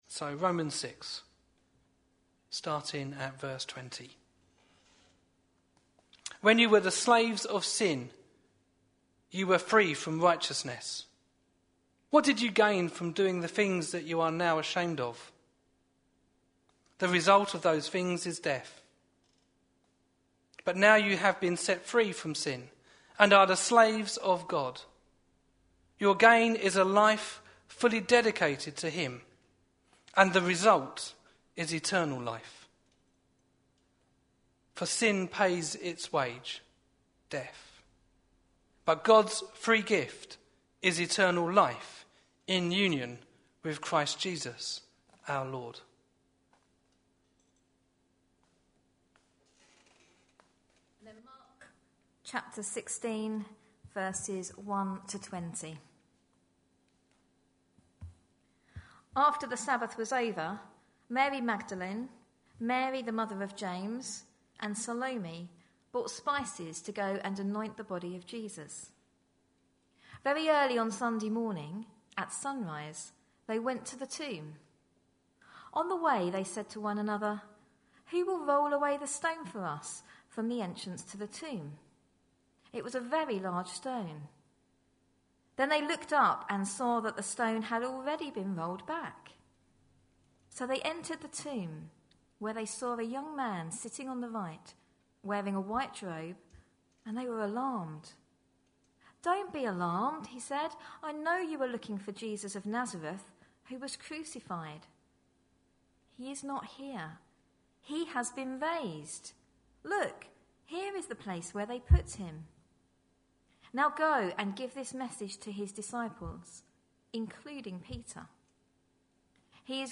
A sermon preached on 20th April, 2014, as part of our Shaken, Not Stirred series.